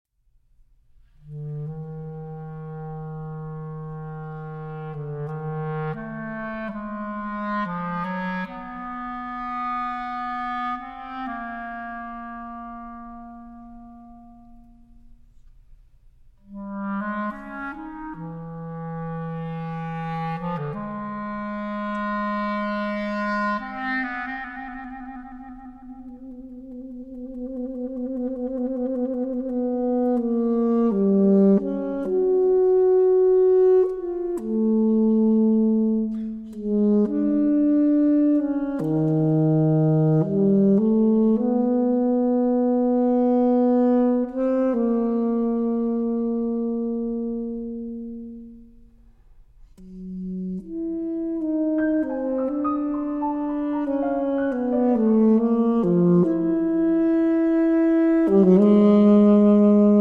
Saxophone
Clarinet
Piano